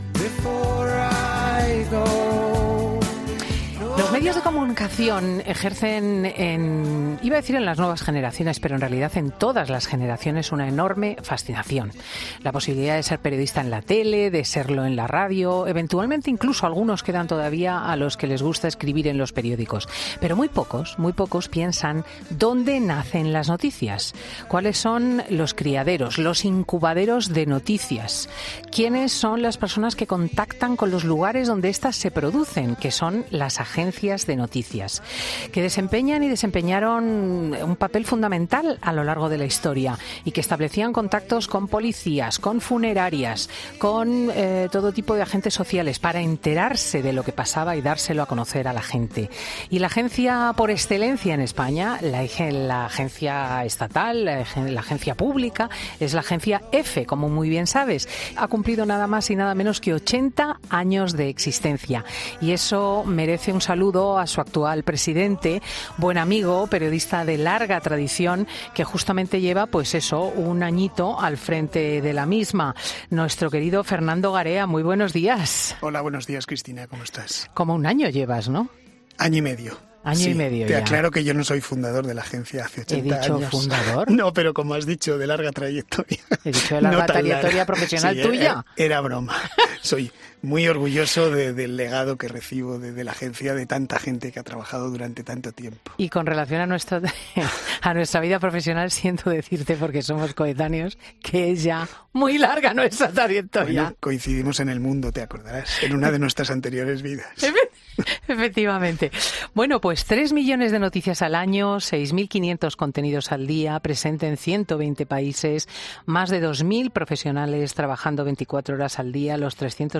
Durante la entrevista, reflexionaban sobre el futuro del periodismo y los cambios que han acaecido a lo largo de la última década.